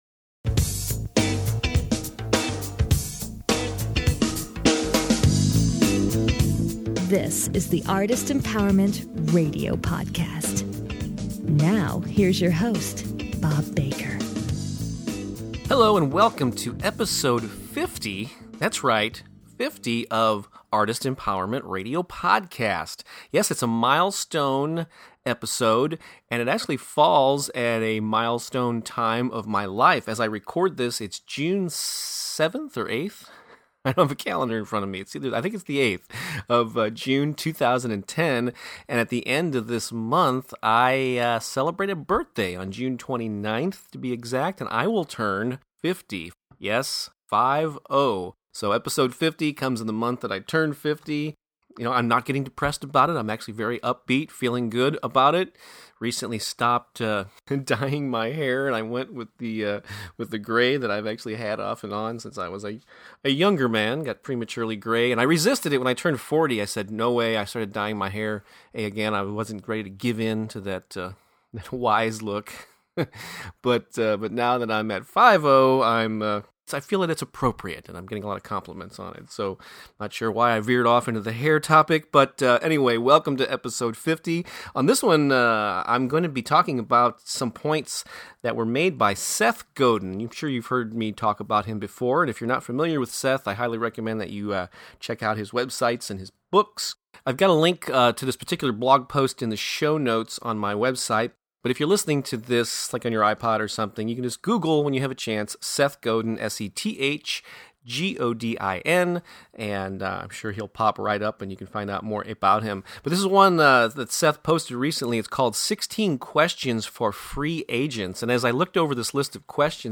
The show intro music is the beginning groove